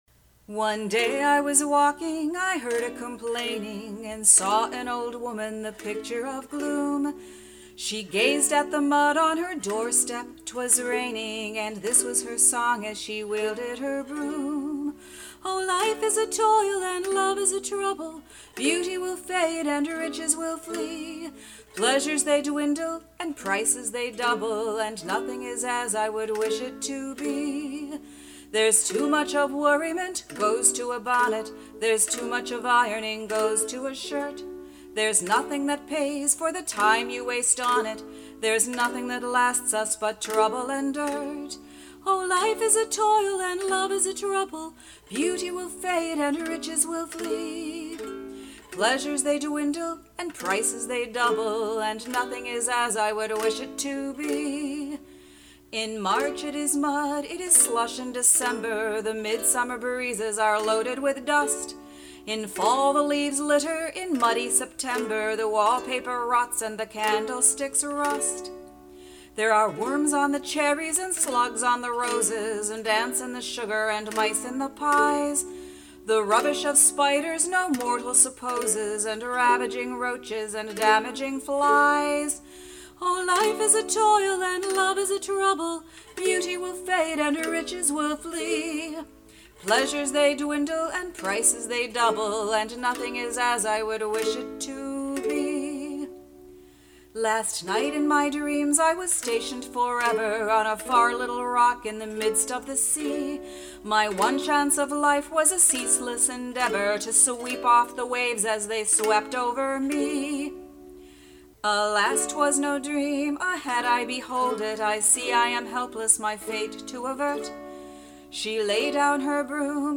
The bumper music for this podcast is a 150-year old song from the American Civil War called "The Housewife's Lament."
CLICK HERE to download the MP3 file of "The Housewife's Lament." (That's me and my trusty ukulele.)